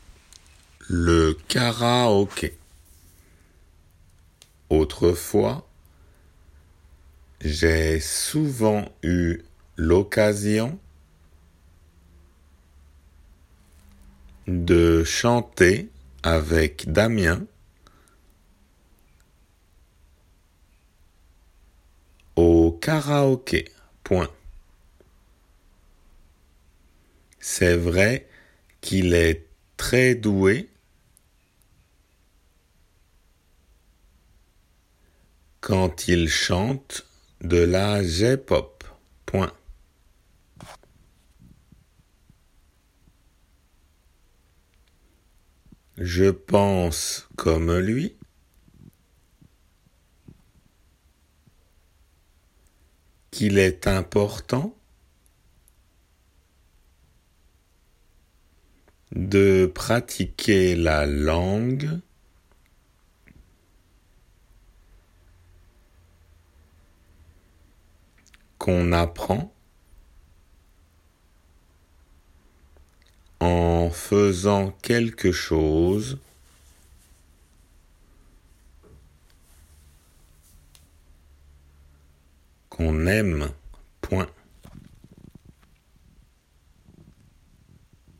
デイクテの速さで